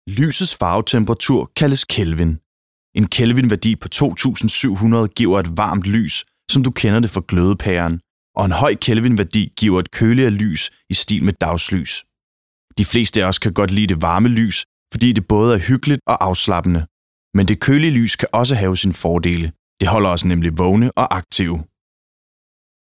Mand
20-30 år